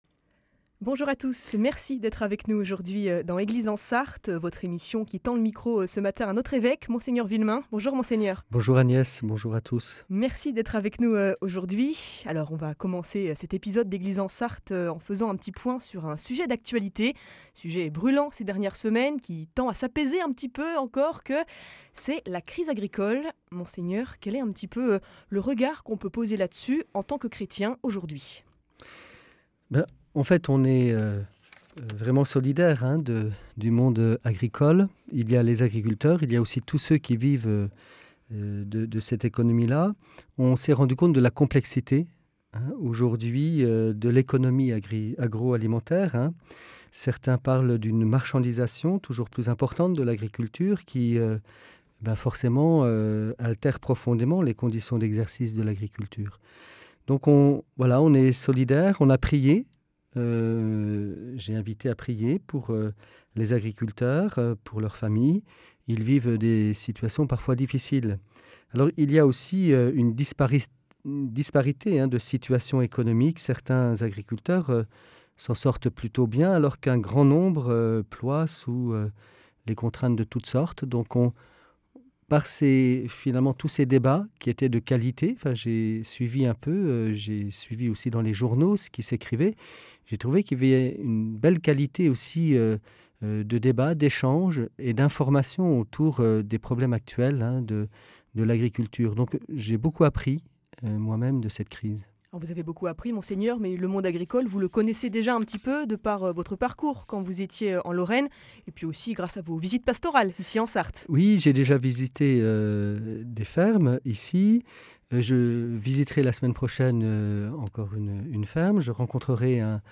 Prise de parole de Mgr Vuillemin au micro de RCF Sarthe